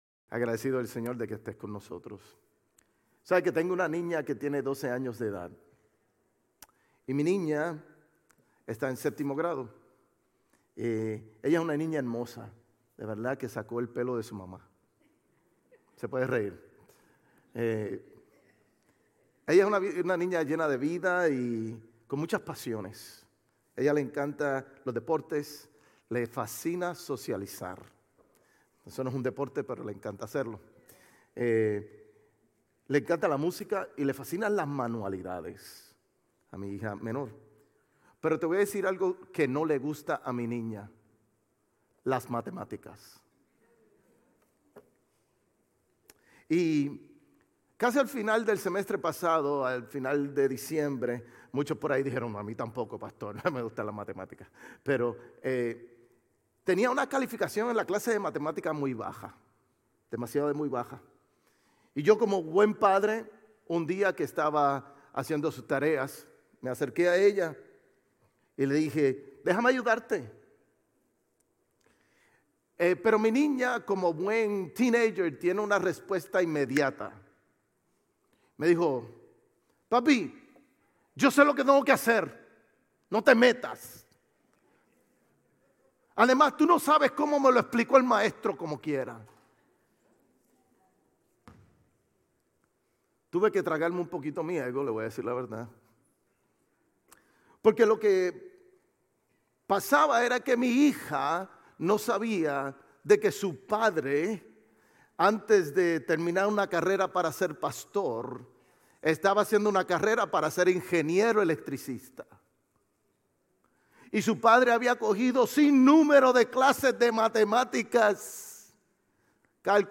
Sermones Grace Español 3_9 Grace Español Campus Mar 10 2025 | 00:37:01 1x 00:00 / 00:37:01 Subscribe Share RSS Feed Share Link Embed